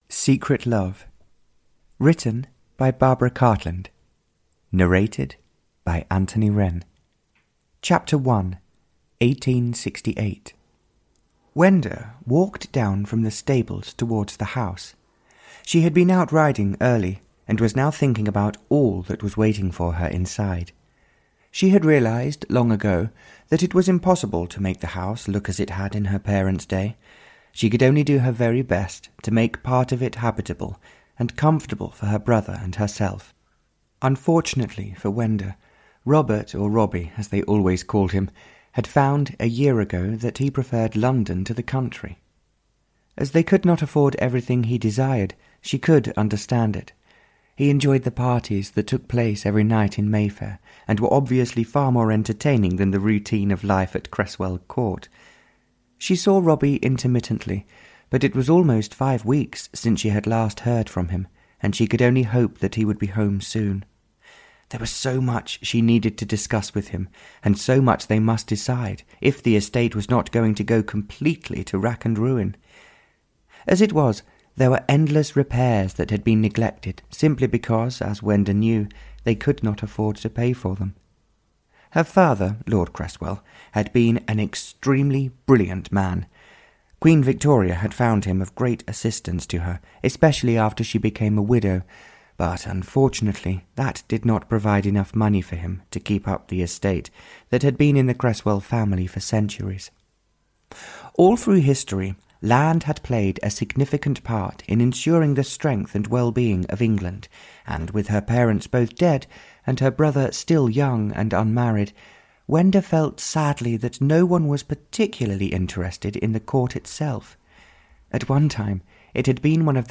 Audio knihaSecret Love (Barbara Cartland s Pink Collection 87) (EN)
Ukázka z knihy